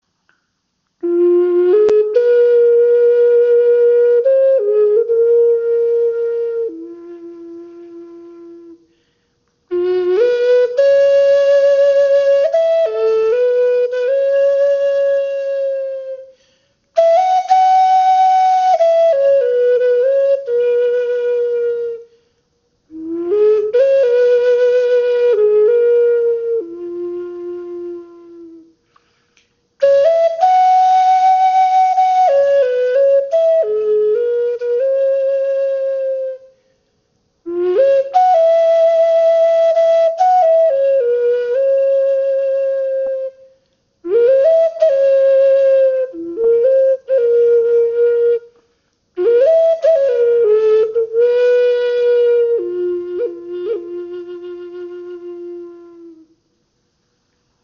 Okarina in F Moll in 432 Hz
• Icon Stimmung: F in 432Hz
Dies ist eine wundervoll verzierte Okarina aus Eukalyptusholz geschnitzt. Sie ist auf den Ton F in 432 Hz gestimmt und erzeugt einen warmen, tragenden Klang.
Obwohl diese Okarina eher klein und handlich ist, erzeugt sie einen angenehm tiefen Ton, fast ebenbürtig zur Nordamerikanischen Gebetsflöte.